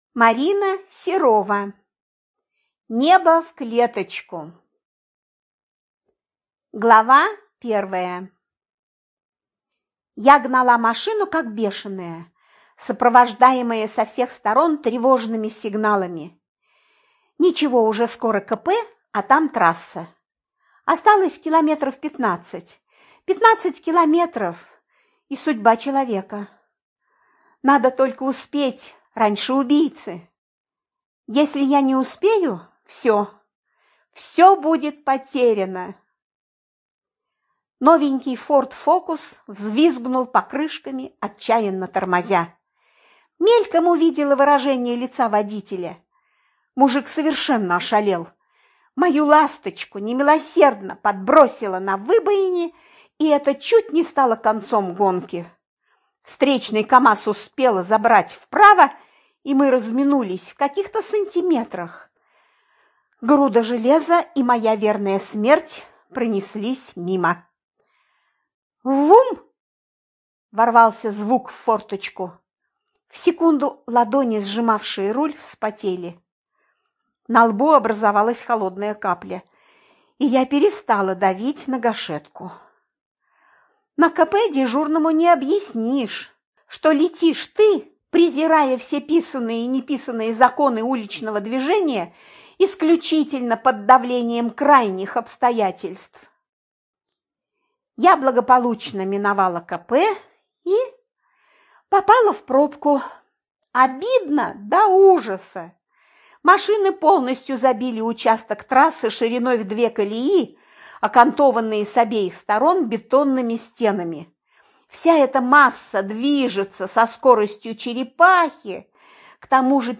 ЖанрДетективы и триллеры